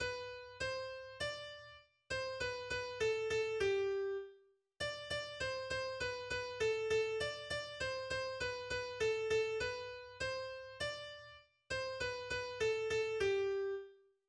Winterliches Kinderlied aus dem späten 18.